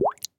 mandrake fvtt13data/Data/modules/mastercrafted/assets/cauldron
bubble1.ogg